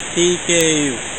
Tech. description: (44.1k, 16bit, mono)